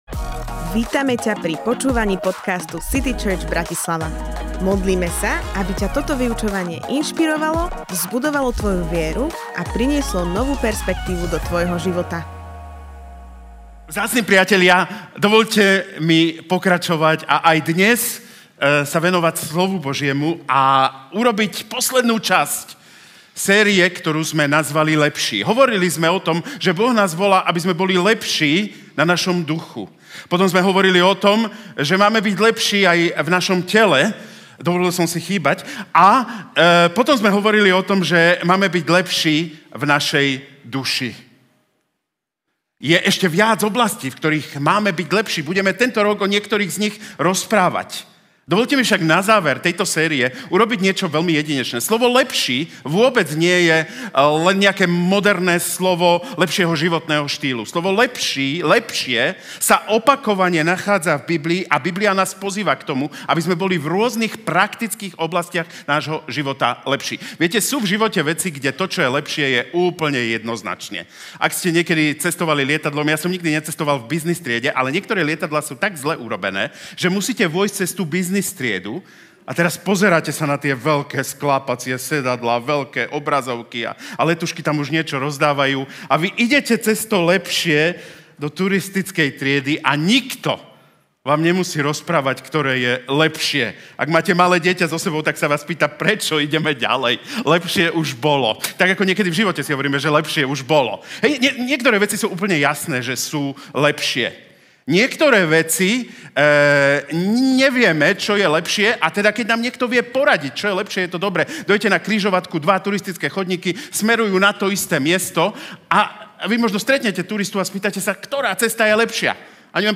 7x LEPŠÍ Kázeň týždňa Zo série kázní